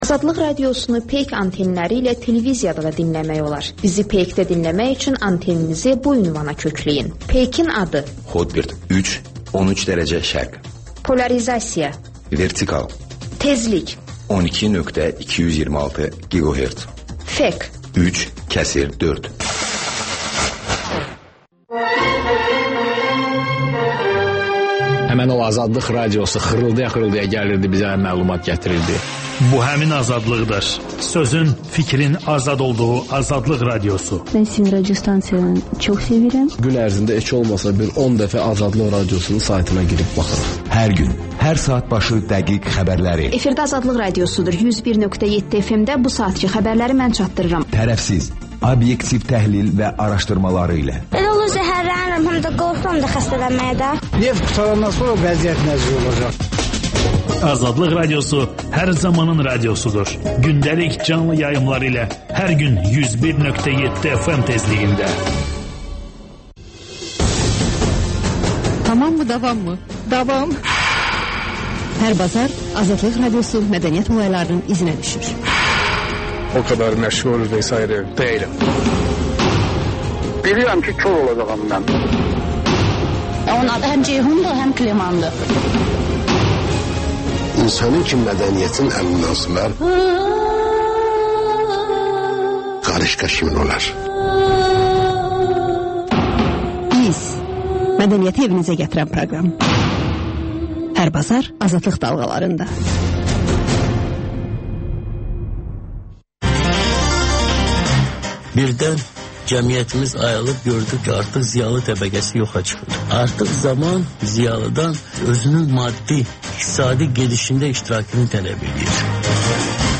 Müxbirlərimizin həftə ərzində hazırladıqları ən yaxşı reportajlardan ibarət paket (Təkrar)